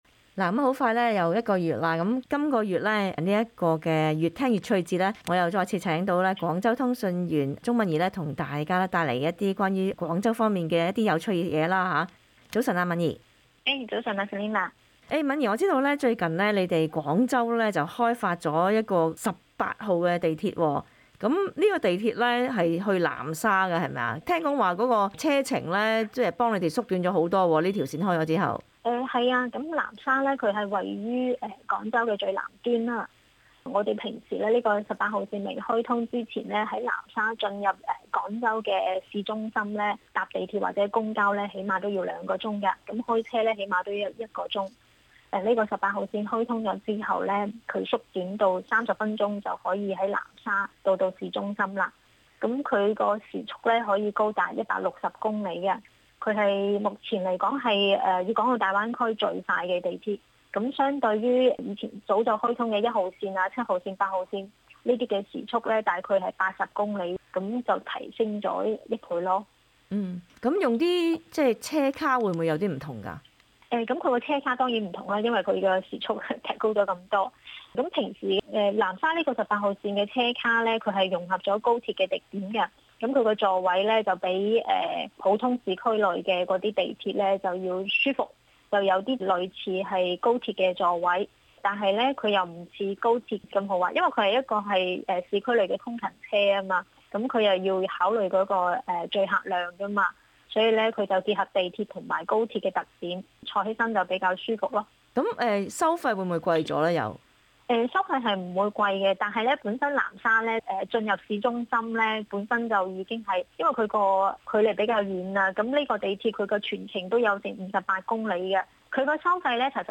另外，通訊員亦會與大家分享有關廣州的健康醫療卡方面的消息。